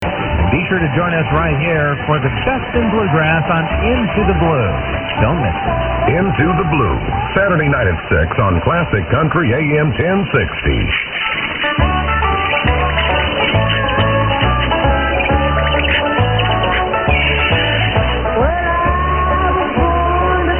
A good recording of AM 10-60 and a possible KDAL id on 610.